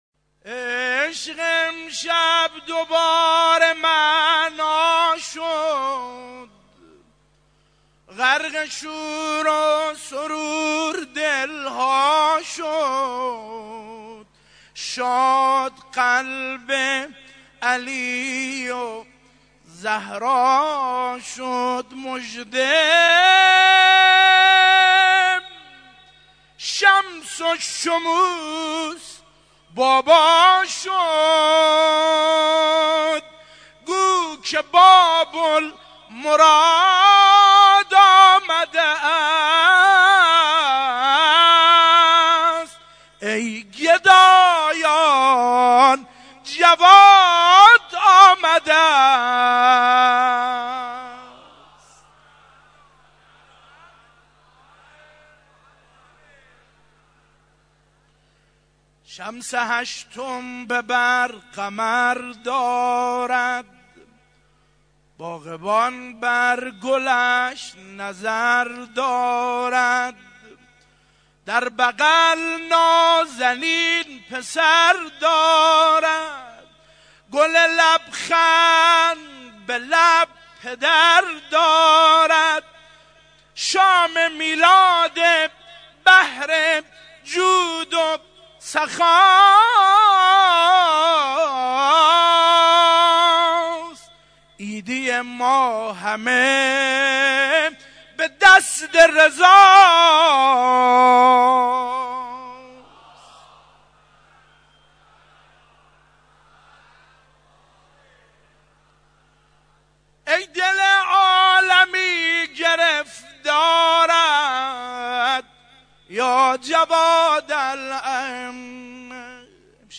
صوتی | مدیحه خوانی